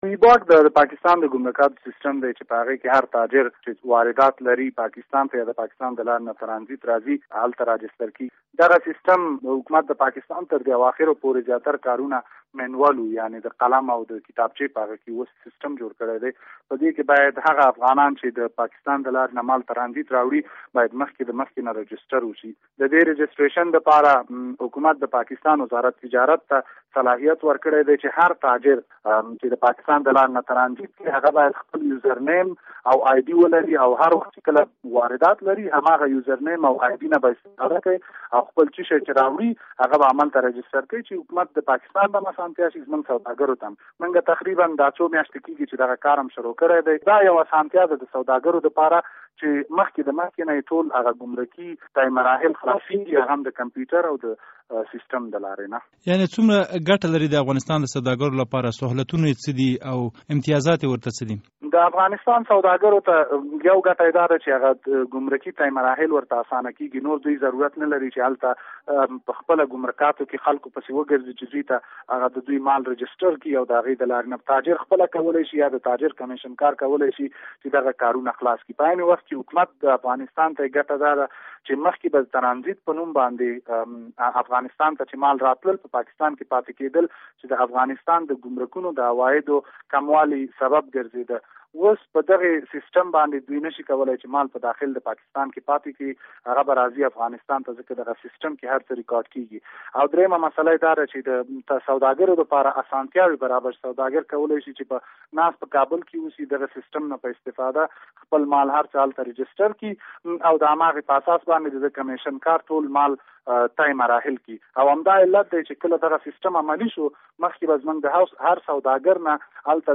له مزمل شینواري سره مرکه